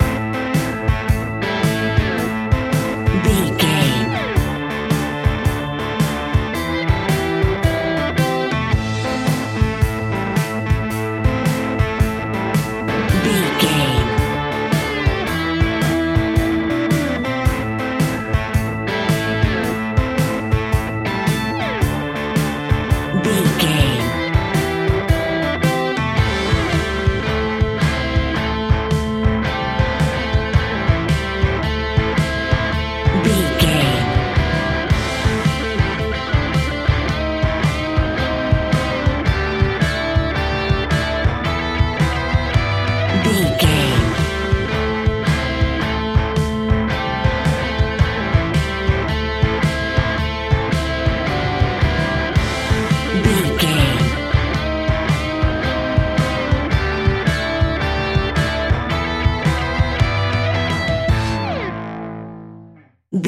Ionian/Major
hard rock
blues rock
distortion
instrumentals